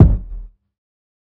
TC2 Kicks8.wav